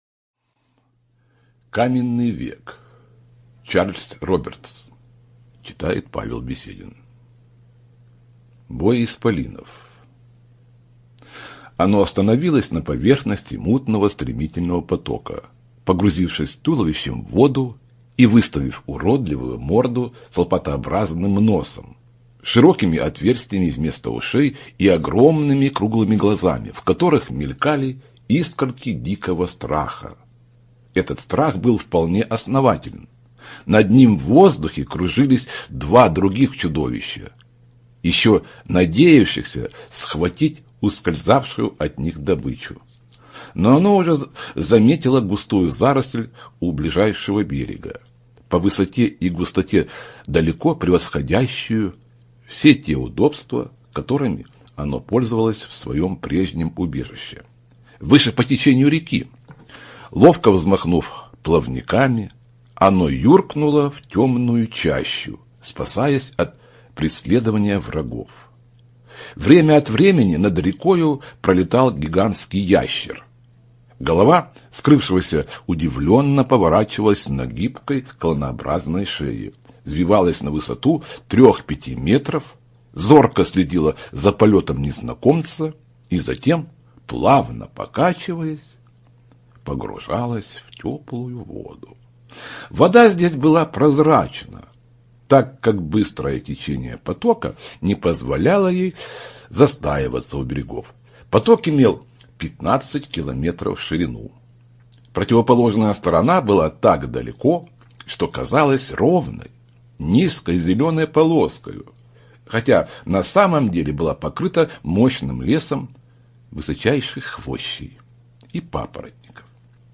ЖанрПриключения, Историческая проза